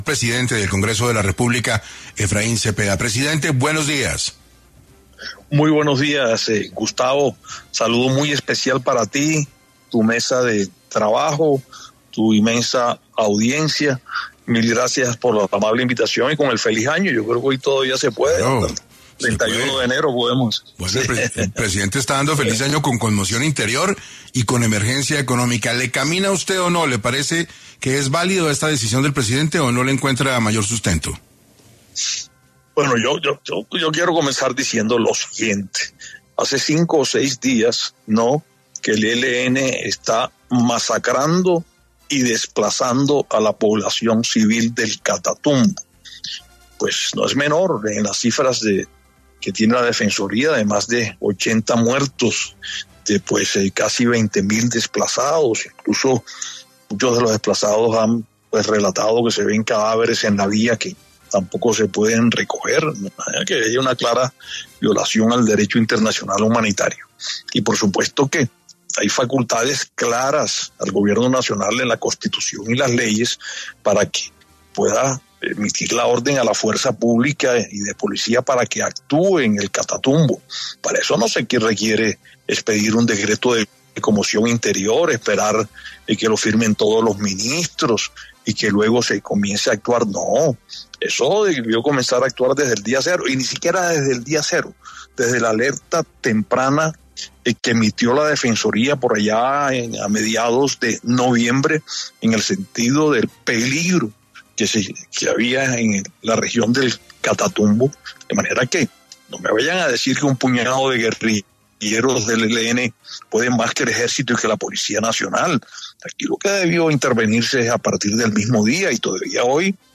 Efraín Cepeda dio su opinión en el programa 6AM sobre la declaratoria de estado de conmoción interior por ataques del ELN en Catatumbo por parte del presidente Gustavo Petro